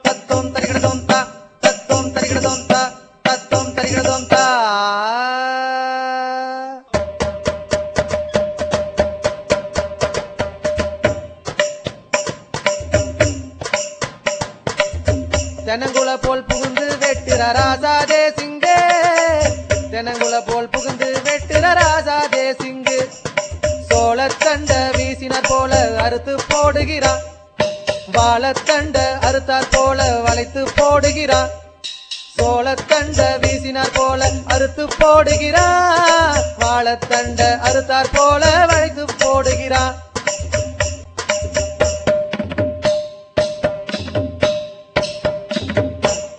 பாடலில் அமைந்த ஒலிநயம் குதித்தோடும் நீலவேணி (மோவுத்துக்காரனின் குதிரை), பாராசாரிக் (தேசிங்கின் குதிரை) குதிரைகள் போலவே குதித்துச் செல்கிறது.